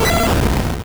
Cri de Raikou dans Pokémon Or et Argent.